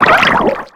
Cri de Nucléos dans Pokémon X et Y.